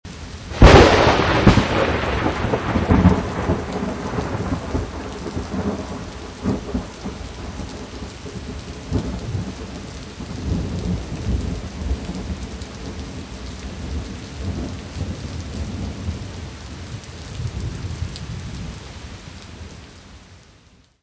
Área:  Fonogramas Não Musicais
L'orage et la pluie
Craquement.